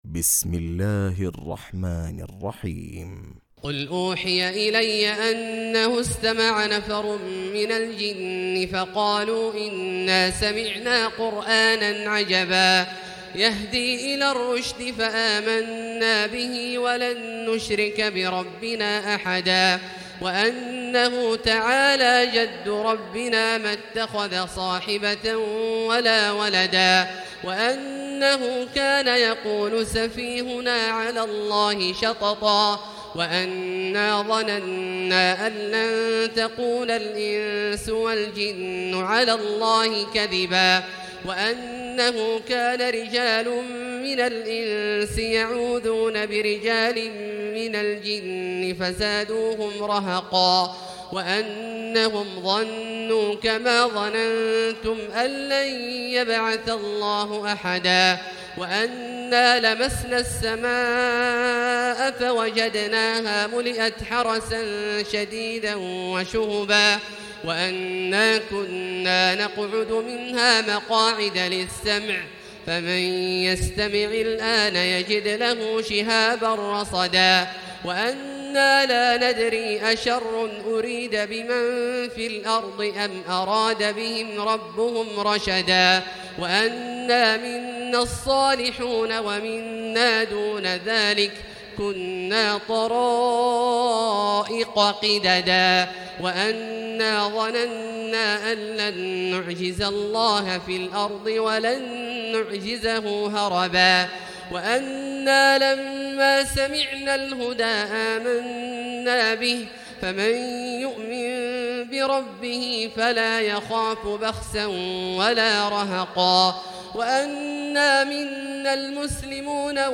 تراويح ليلة 28 رمضان 1439هـ من سورة الجن الى المرسلات Taraweeh 28 st night Ramadan 1439H from Surah Al-Jinn to Al-Mursalaat > تراويح الحرم المكي عام 1439 🕋 > التراويح - تلاوات الحرمين